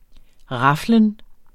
raflen substantiv, fælleskøn Udtale [ ˈʁɑflən ] Betydninger det at spille med terninger; det at kaste terninger med et raflebæger SPROGBRUG sjældent Synonym rafling Der var ingen smalle steder, da der i går blev afholdt Ældre-OL.